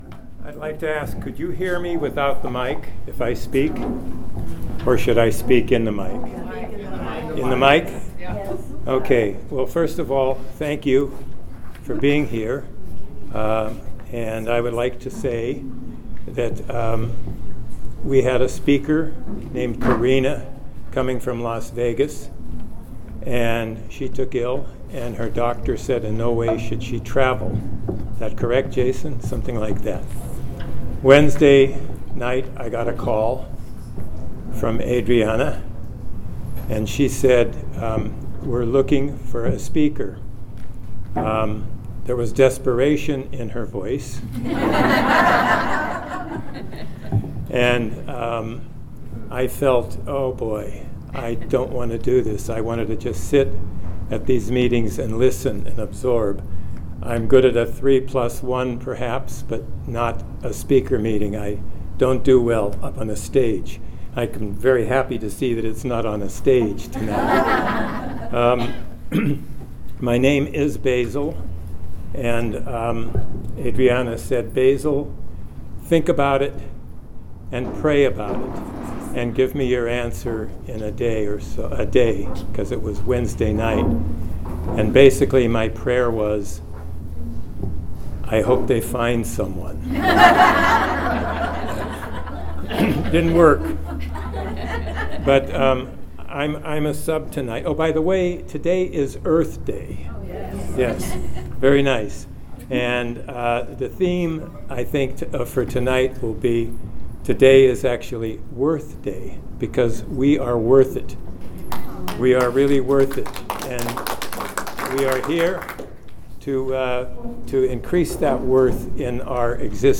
35th Annual Ventura Serenity By The Sea
Ventura CA